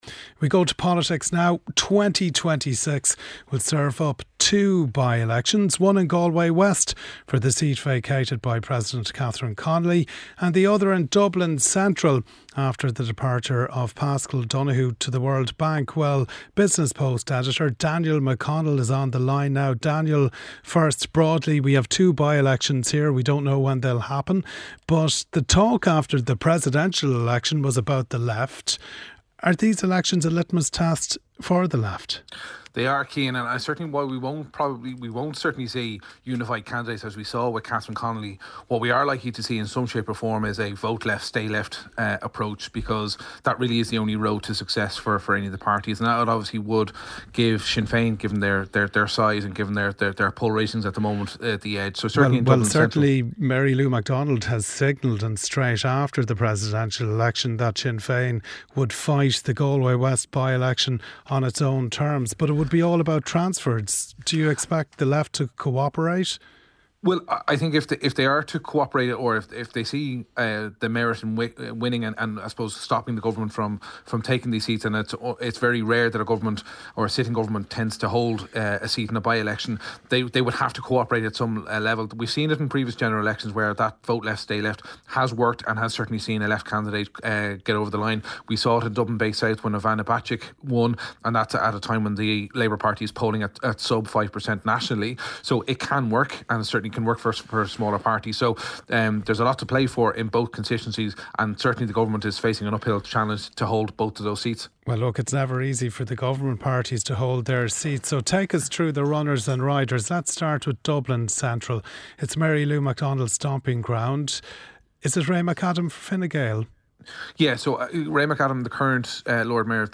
News, sport, business and interviews. Presented by Rachael English.